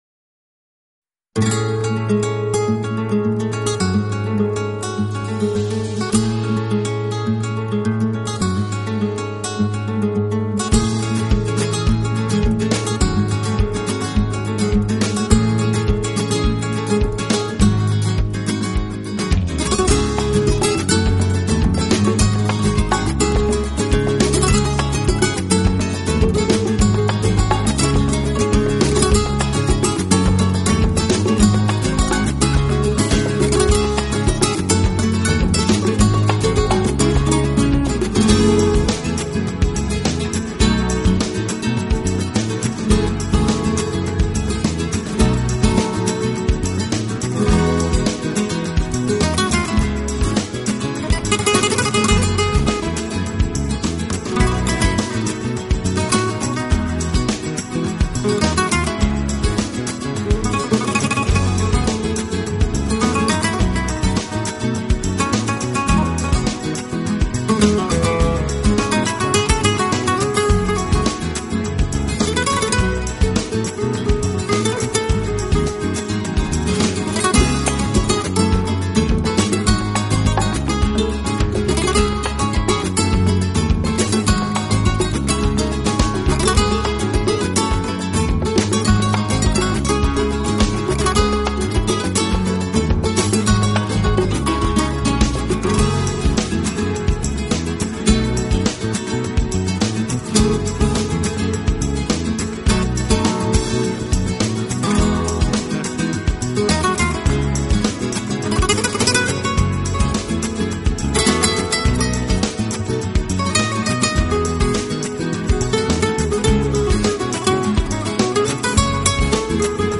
【弗拉门戈吉他】
音乐类型: Pop, flamenco